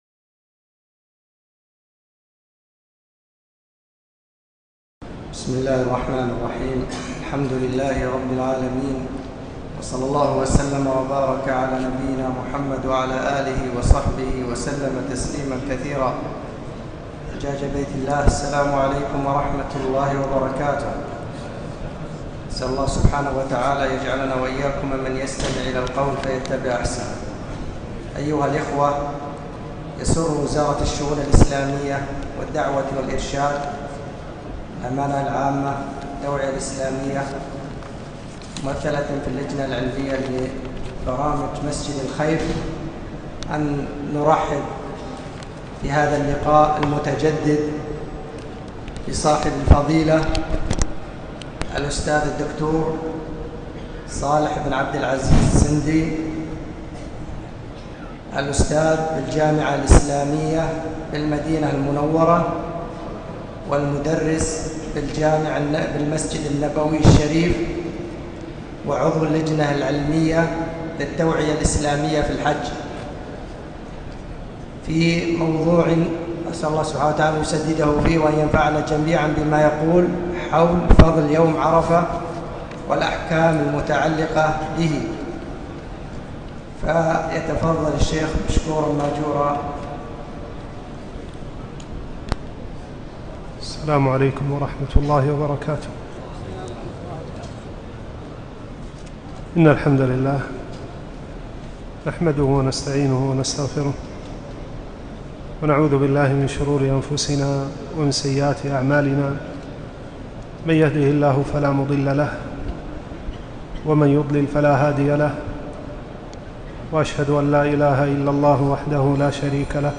محاضرة - فضل يوم عرفه وأحكامه